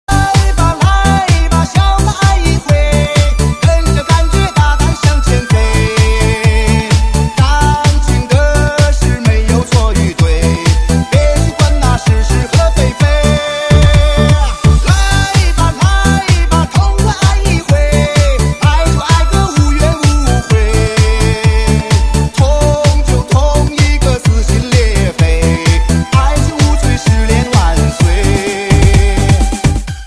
DJ铃声, M4R铃声, MP3铃声 232 首发日期：2018-05-16 00:01 星期三